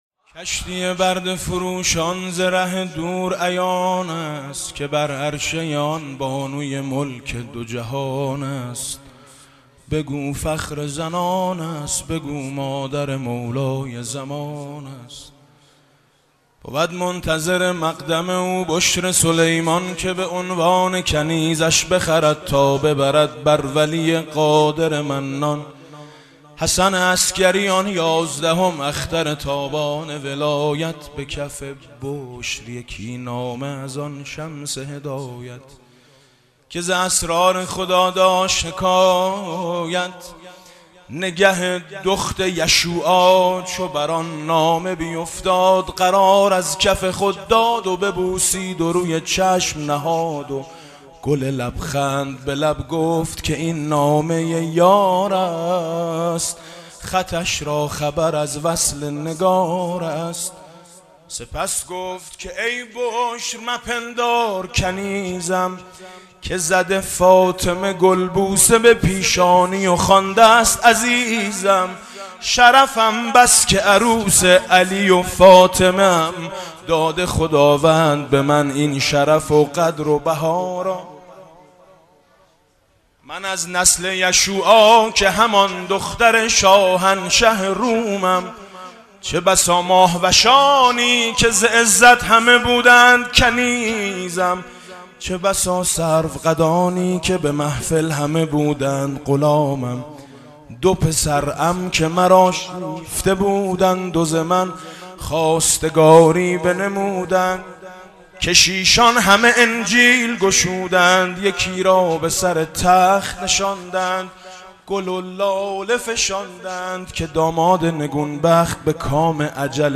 مدح: کشتی برده فروشان ز ره دور عیان است